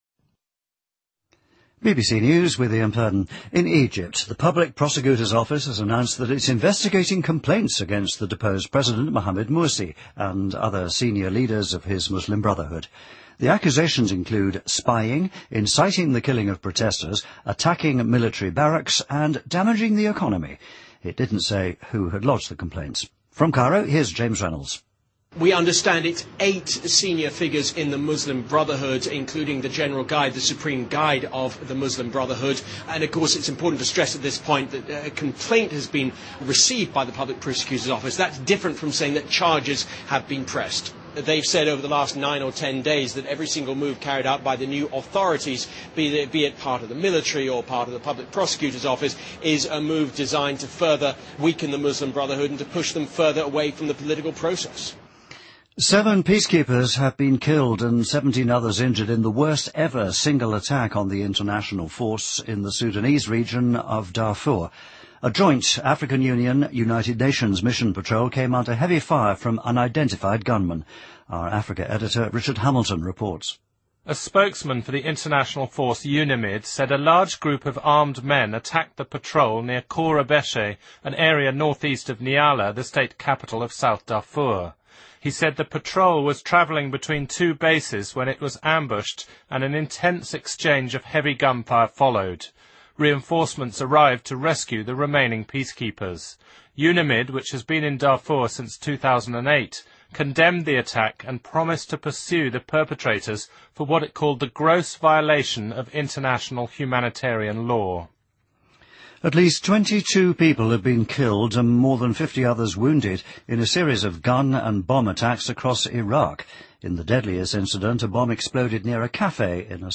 BBC news,苏力台风给中国东部沿海地区带来大规模破坏